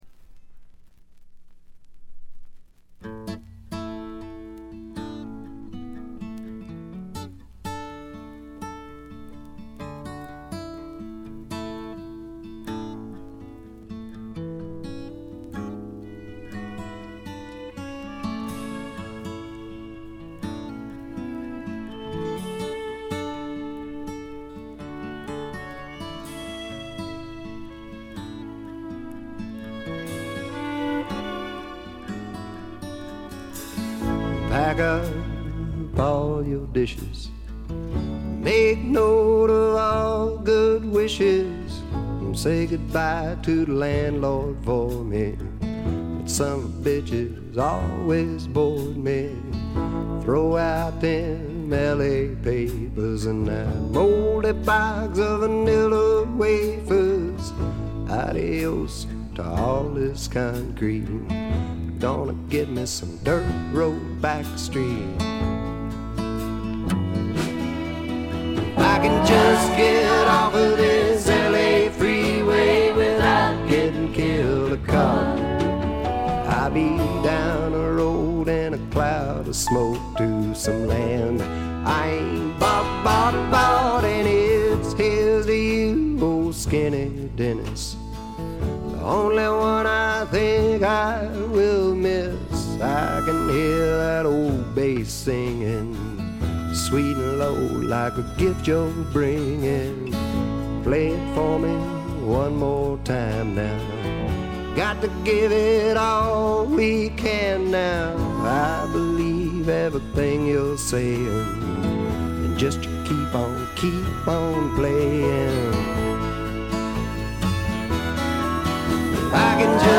軽微なチリプチ少々。
朴訥な歌い方なのに声に物凄い深さがある感じ。
試聴曲は現品からの取り込み音源です。